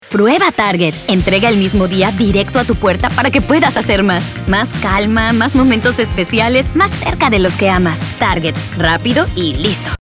Commercial
Enthusiastic - Conversational